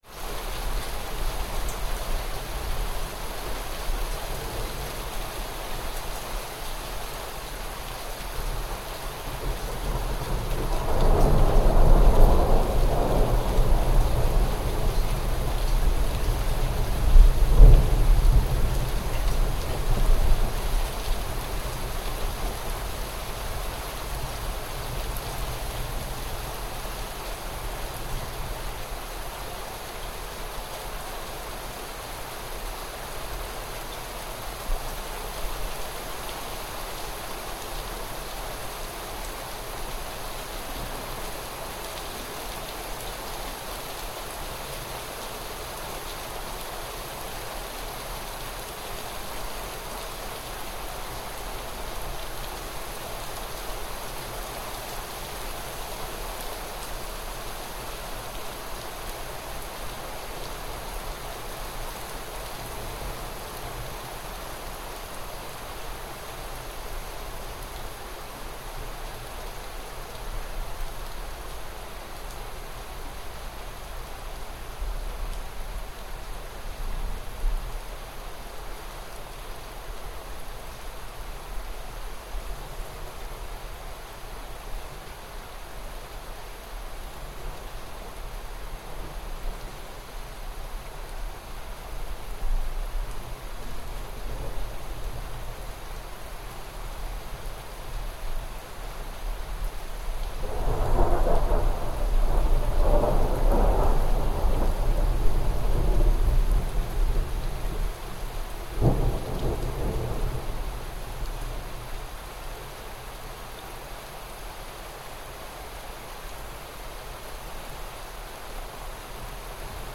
Stormy Weather Soundscape
The sound of distant thunder and falling rain. Experience a stormy weather soundscape that starts with a gentle drizzle, steadily building into a soothing rain. Hear distant thunder rumbling softly, echoing across the landscape to create an immersive and vast atmosphere.
Genres: Sound Effects
Stormy-weather-soundscape.mp3